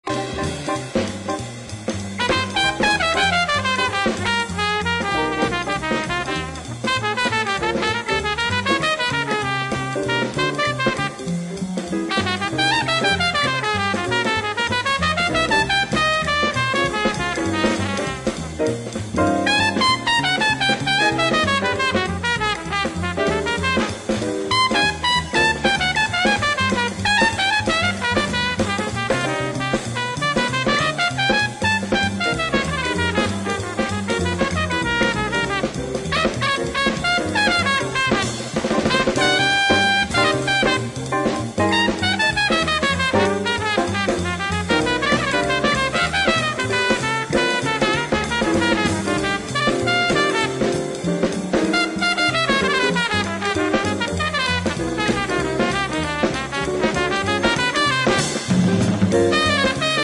Soul